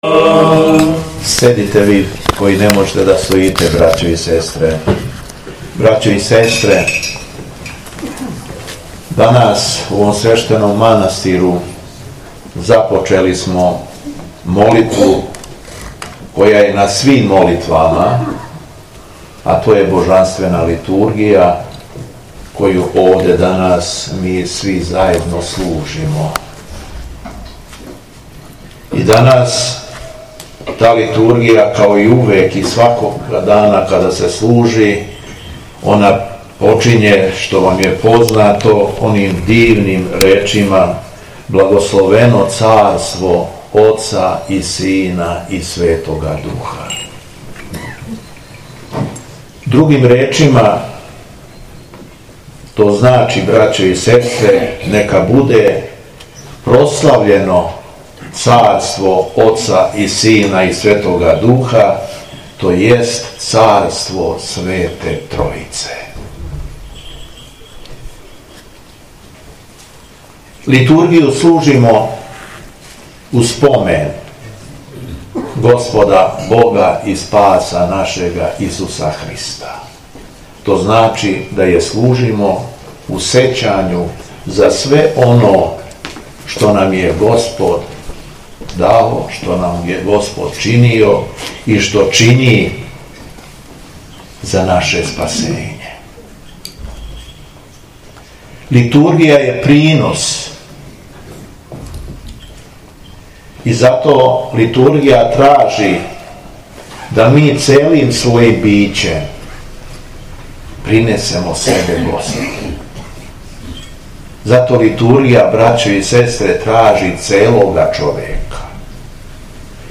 СВЕТА АРХИЈЕРЕЈСКА ЛИТУРГИЈА У МАНАСТИРУ РАЛЕТИНАЦ - Епархија Шумадијска
Беседа Његовог Преосвештенства Епископа шумадијског г. Јована - манастир Ралетинац
Обраћајући се сабранима пригодном беседом, Епископ шумадијски, Господин Г. Јован верном народу рекао је: